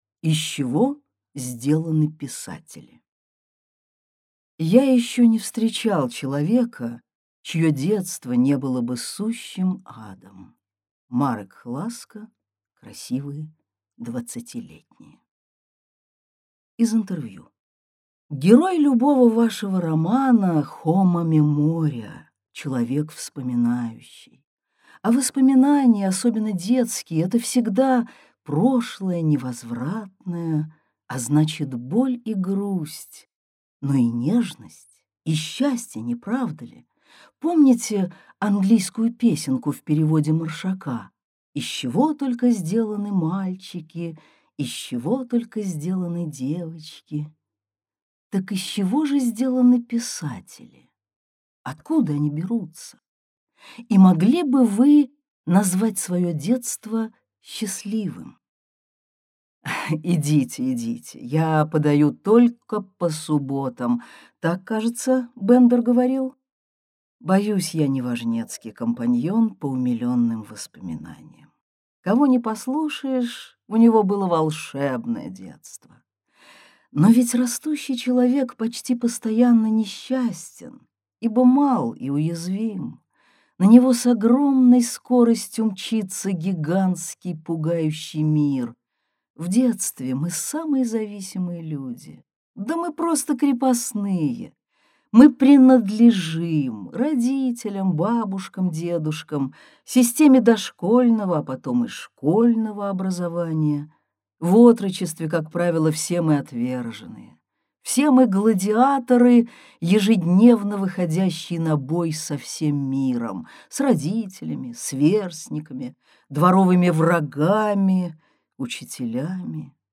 Аудиокнига Одинокий пишущий человек - купить, скачать и слушать онлайн | КнигоПоиск